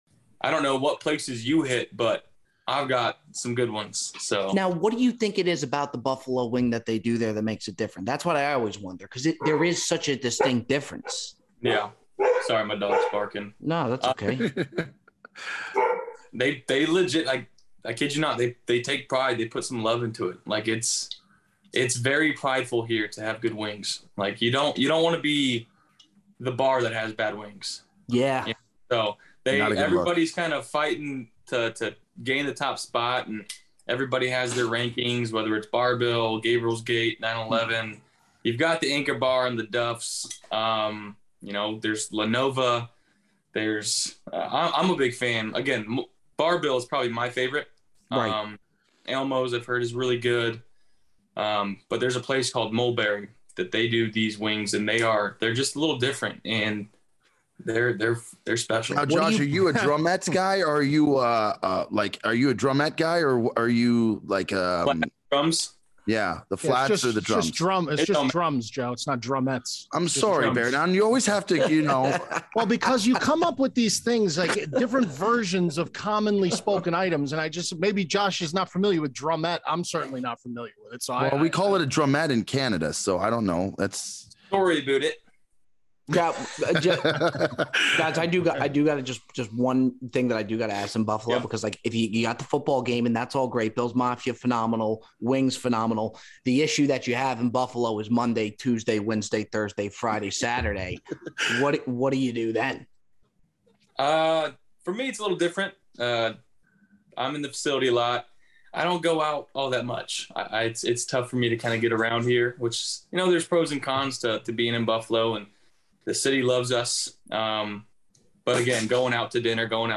Josh Allen Interview - Bonus Episode
The boys have the pleasure of welcoming Josh Allen, Quarterback of the Buffalo Bills, to the program. They spoke with Josh a few months back and we felt it appropriate to release the full, uncut episode for the faithful right before the first game of the season tonight where the Bills take on the Superbowl champs, LA Rams.